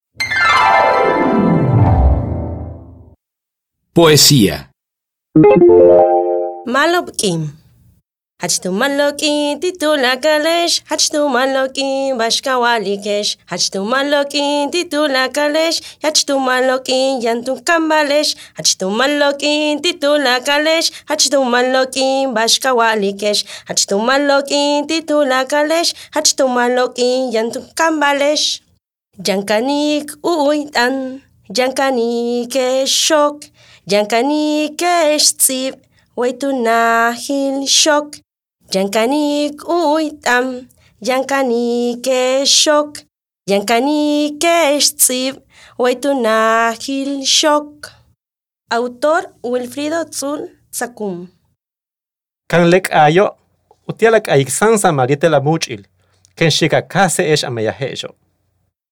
Ma’alob k’iin (k’aay)